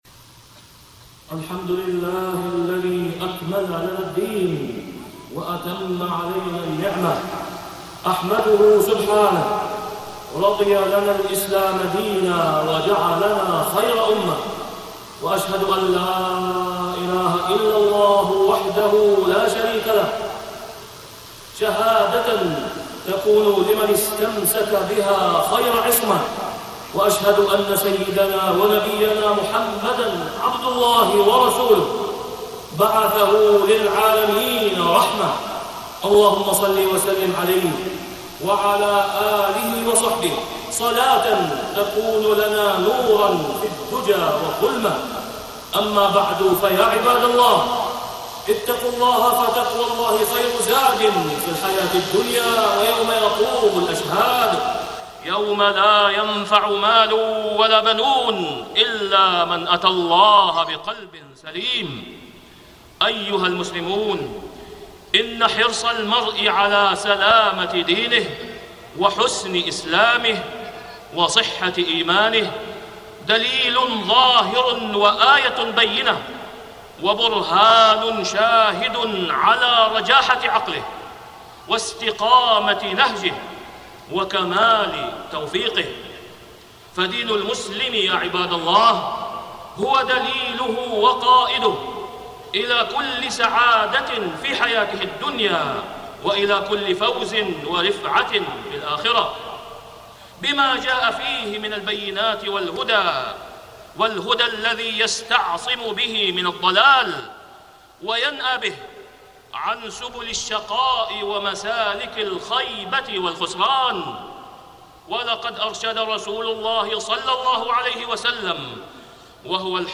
تاريخ النشر ١٦ محرم ١٤٢٦ هـ المكان: المسجد الحرام الشيخ: فضيلة الشيخ د. أسامة بن عبدالله خياط فضيلة الشيخ د. أسامة بن عبدالله خياط من حسن الإسلام